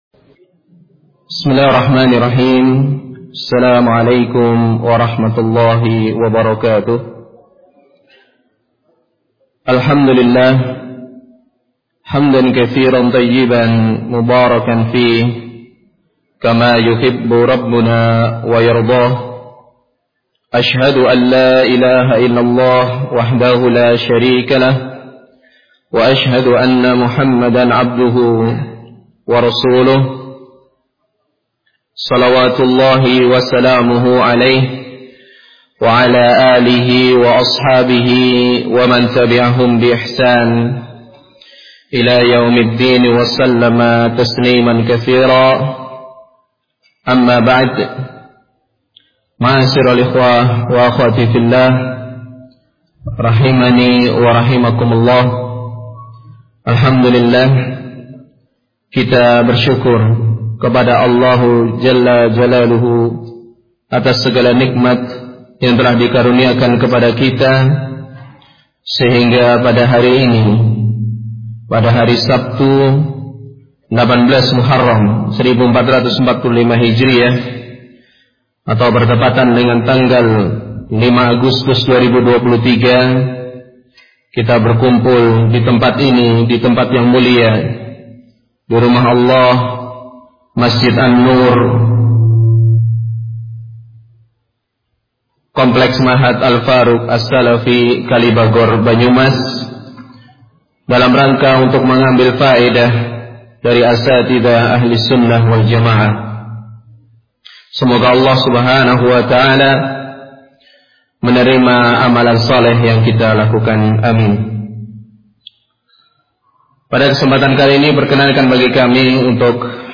💽 🔊 Audio Rekaman 🔊 💽 ☑ Rangkaian Muhadharah Ilmiyah Kalobagor 2 Hari 🕌 Tempat : Masjid An Nuur [Komplek Ma'had Al Faruq As-Salafy Kalibagor, Kec. Kalibagor, Kab. Banyumas - Jawa Tengah]